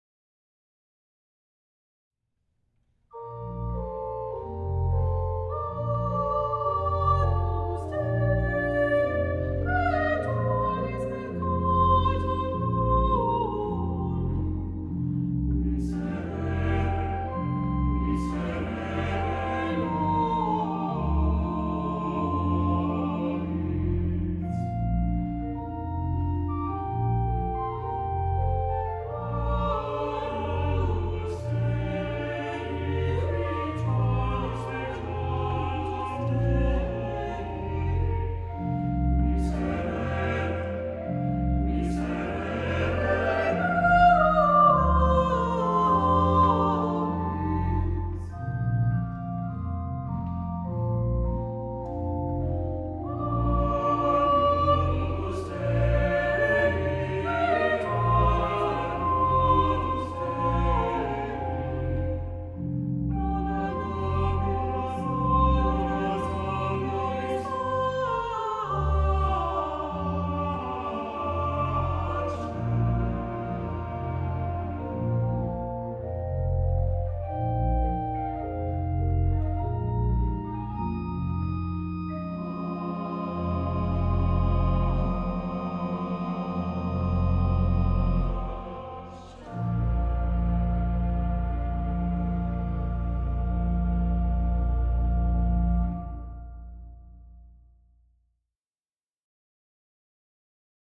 Live, documentary recordings made during Cathedral liturgies.
Holy Name Cathedral Choirs – Grayston Ives – Agnus Dei